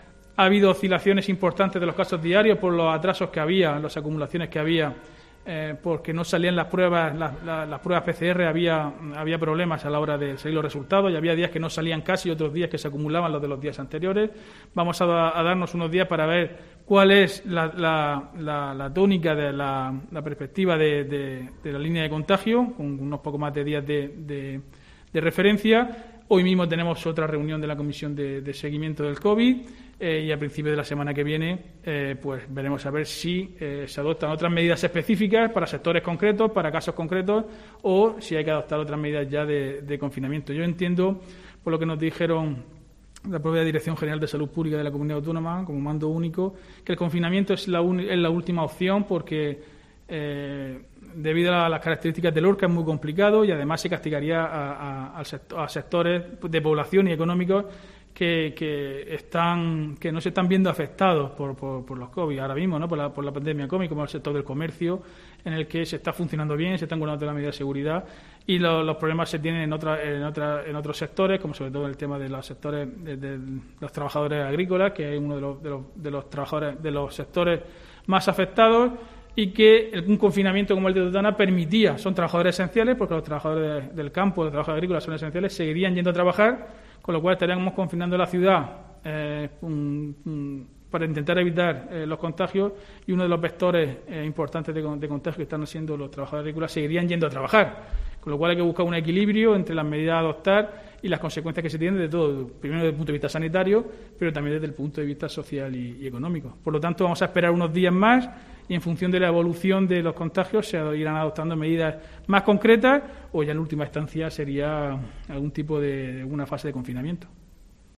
Diego José Mateos, alcalde de Lorca sobre situación COVID 19